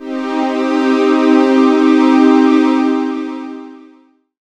37i02pad1-c.wav